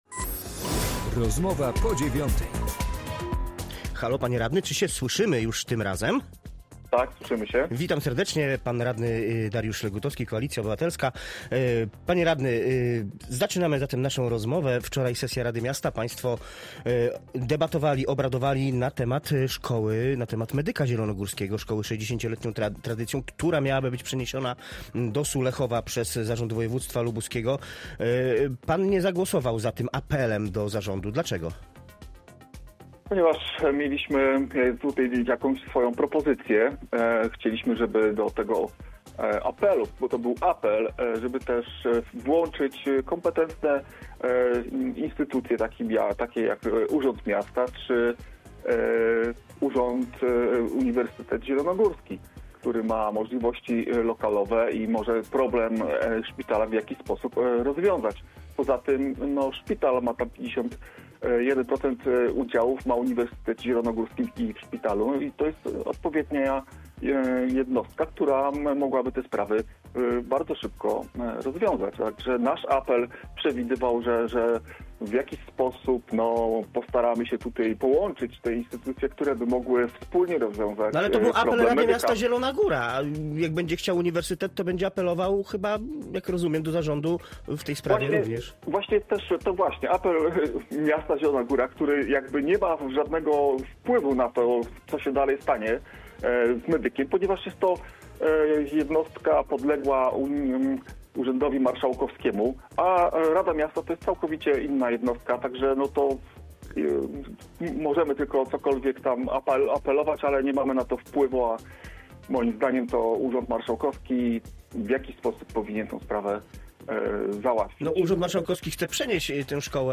Dariusz Legutowski, radny miejski (PO)